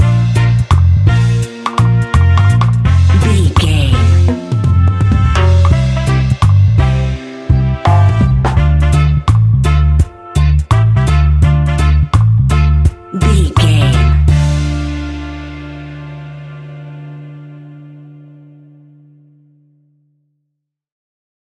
Classic reggae music with that skank bounce reggae feeling.
Aeolian/Minor
D
instrumentals
laid back
chilled
off beat
drums
skank guitar
hammond organ
percussion
horns